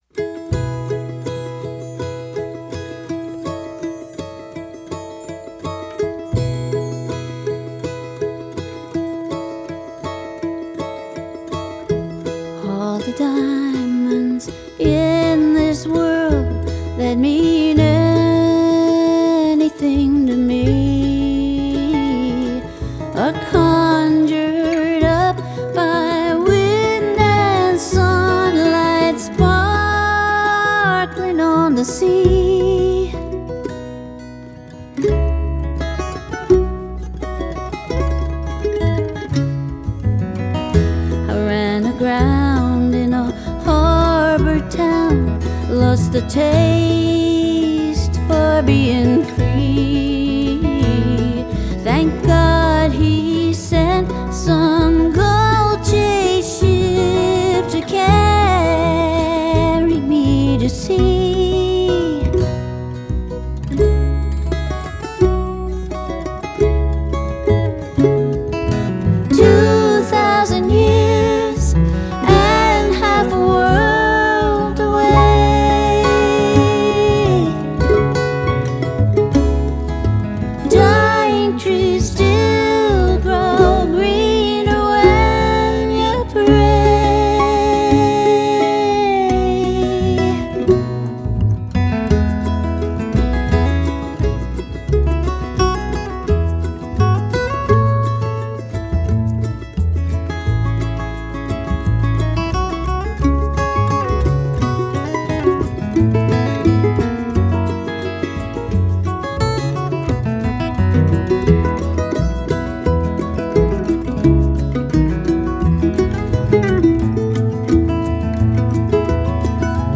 Bluegrass Music